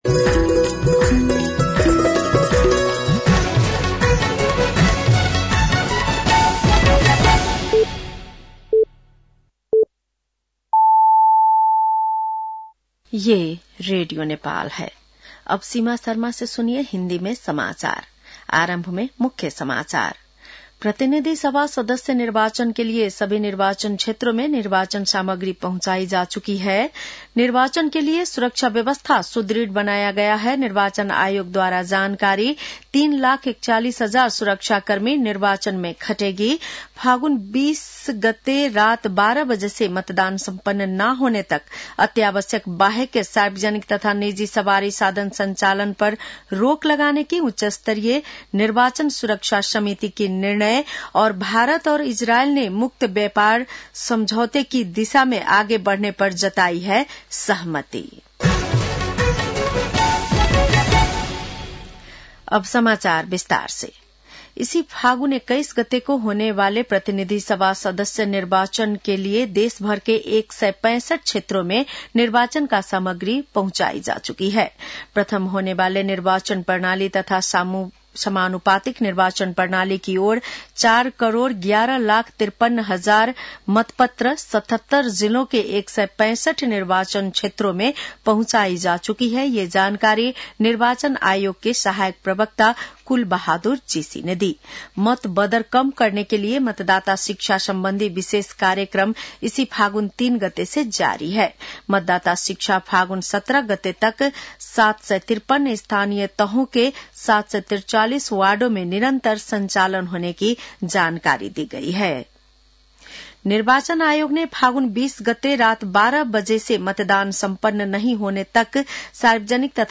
बेलुकी १० बजेको हिन्दी समाचार : १४ फागुन , २०८२